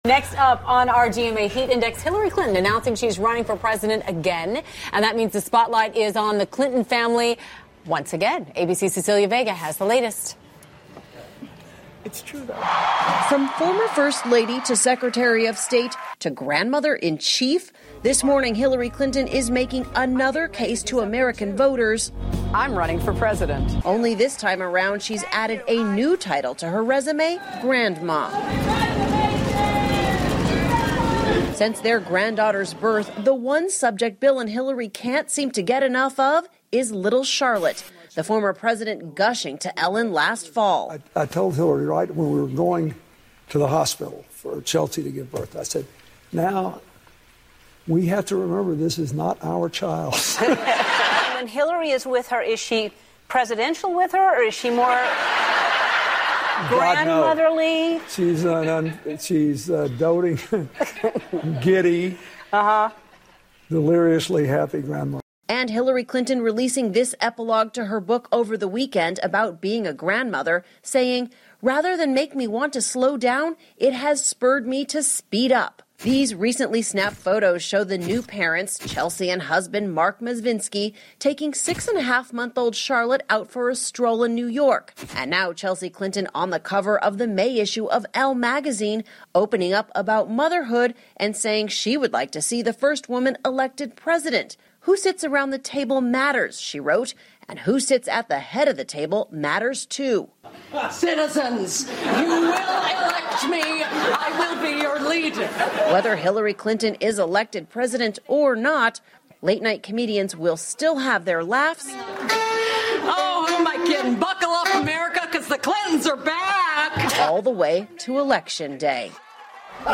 访谈录 希拉里·克林顿参加2016年美国总统大选 听力文件下载—在线英语听力室